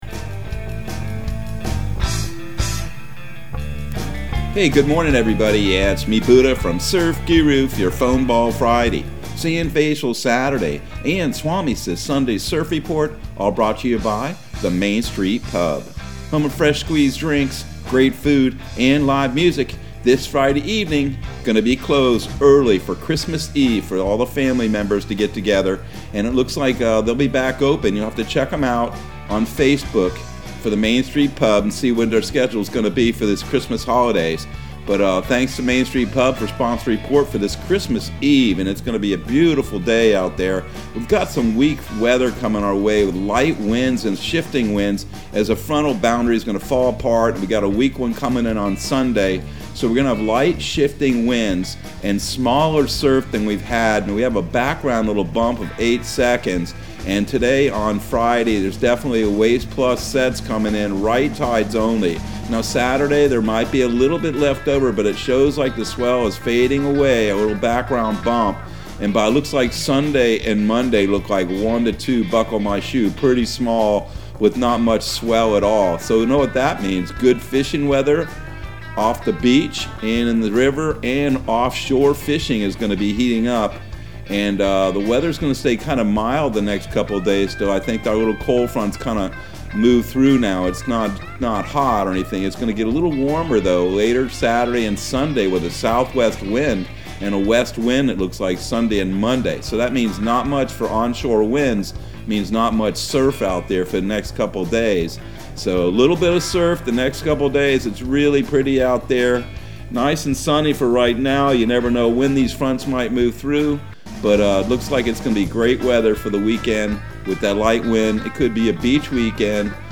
Surf Guru Surf Report and Forecast 12/24/2021 Audio surf report and surf forecast on December 24 for Central Florida and the Southeast.